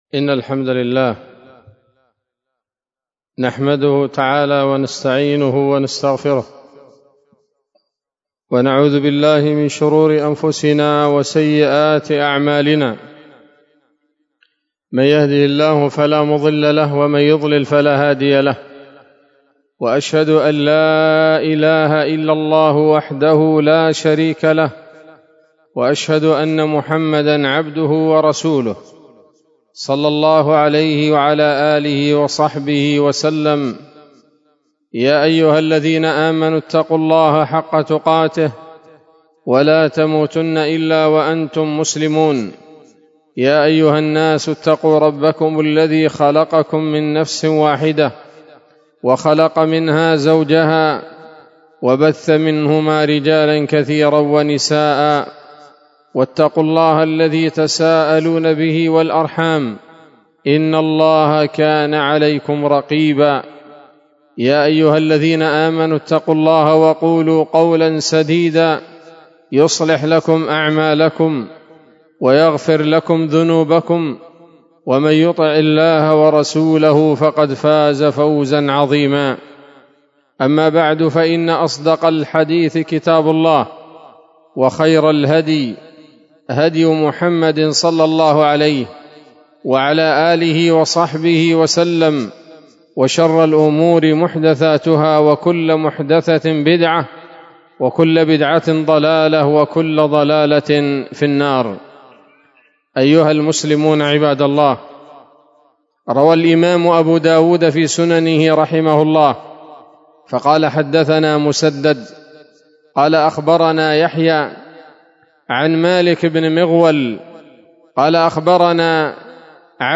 خطبة جمعة بعنوان: (( الاسم الأعظم )) 6 شوال 1446 هـ، دار الحديث السلفية بصلاح الدين